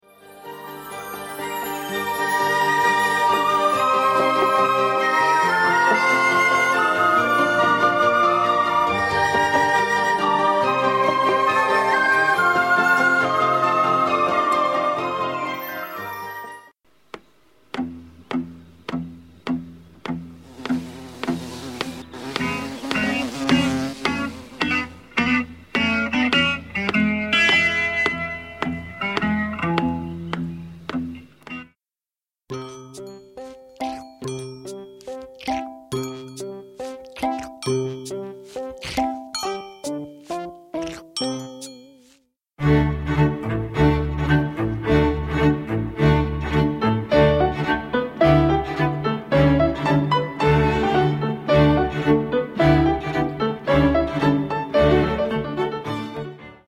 Det består av fyra sånger (S1-S4).